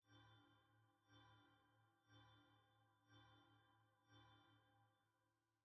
sfx_ui_map_vfx_settlementready.ogg